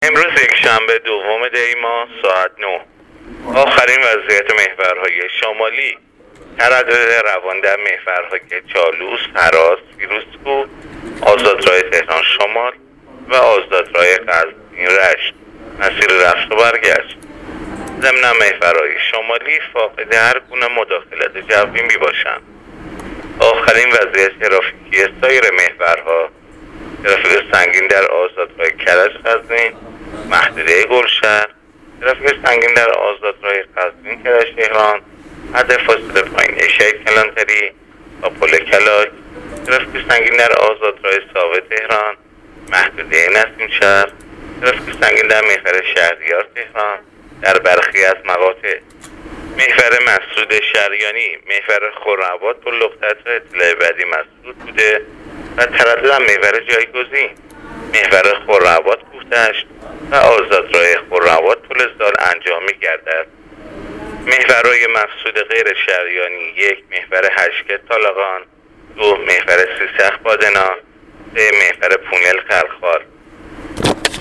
گزارش رادیو اینترنتی از آخرین وضعیت ترافیکی جاده‌ها تا ساعت ۹ دوم دی؛